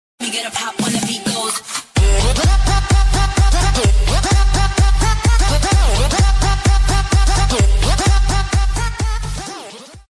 telefon-zil-sesi-enerjik-2018.mp3